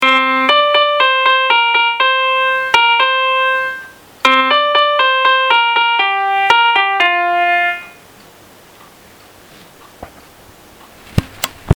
民謡？その2　ダウンロード